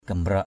/ɡ͡ɣa-mraʔ/ (d.) mang cá.